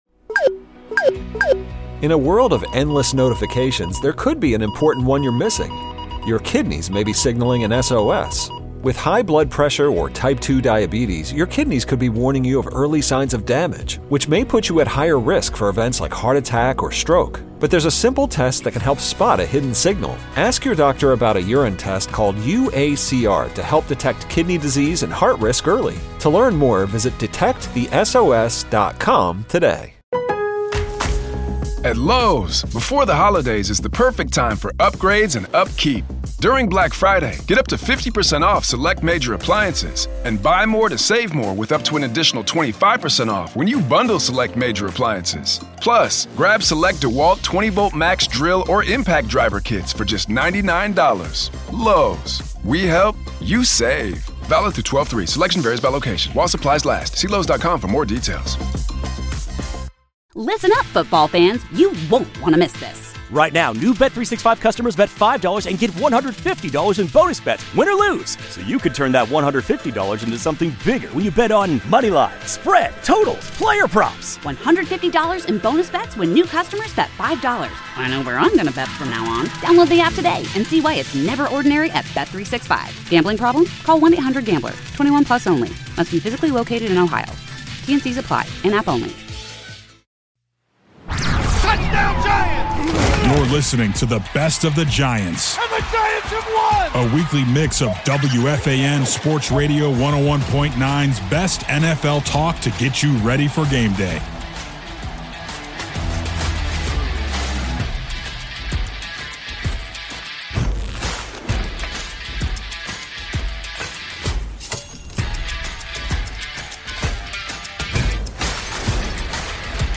Transcript Click on a timestamp to play from that location 0:00.0 You're listening to the best of the Giants. 0:05.2 And the Giants have won! 0:06.6 A weekly mix of WFAN Sports Radio 101.9's best NFL talk to get you ready for game day.